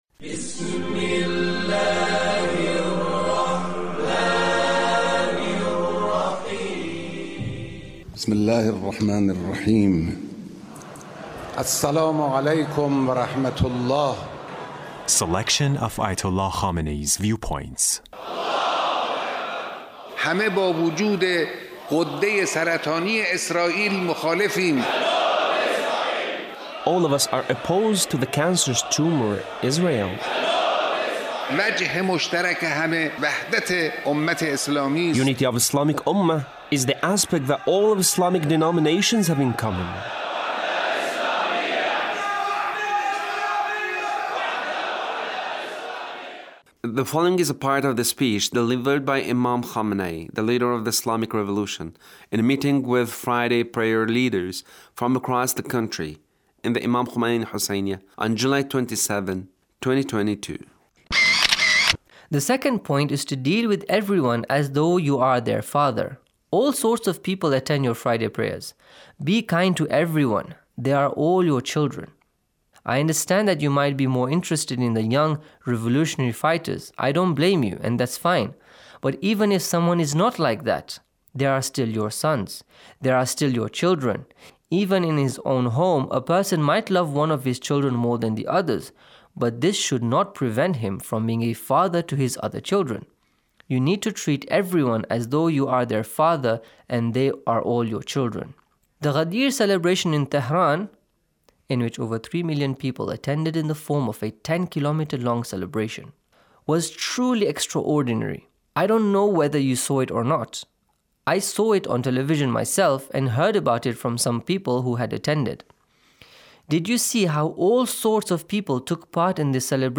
Leader's Speech on a Gathering with Friday Prayer Leaders